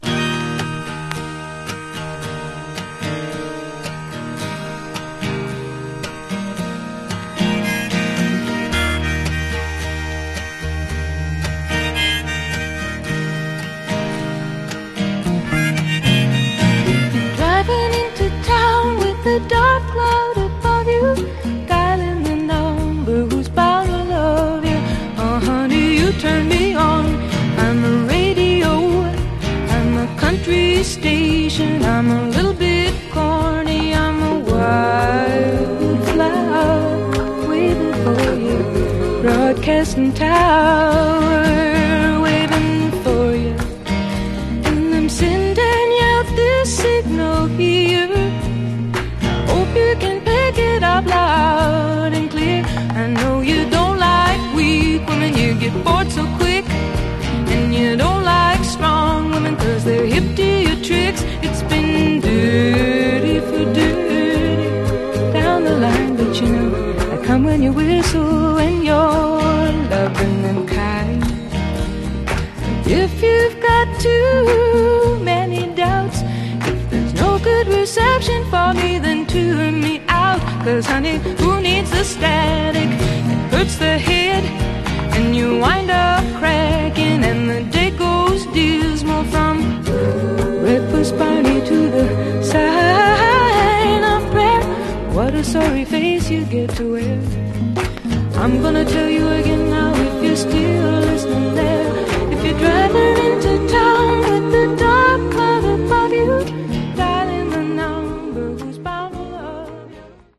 Genre: Folk Rock